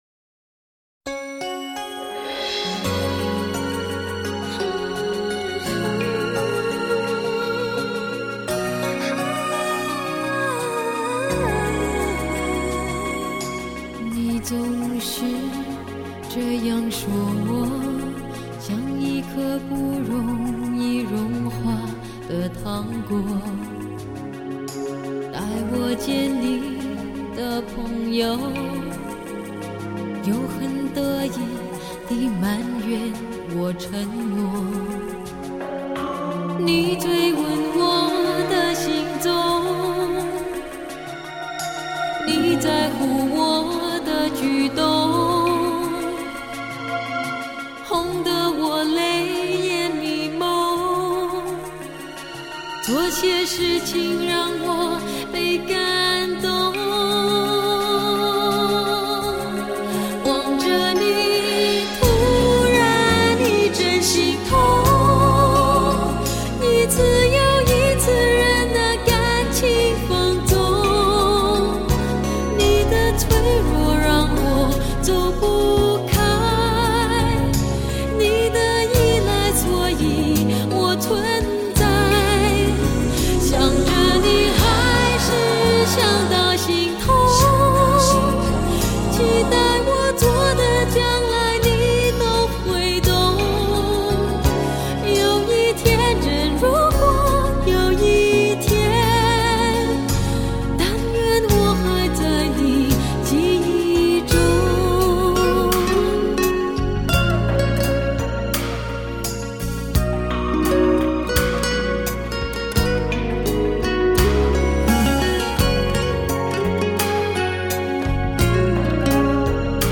音色更接近模拟(Analogue)声效
强劲动态音效中横溢出细致韵味
发自心底深处的独特嗓音有一种很自然、使人感动的魔力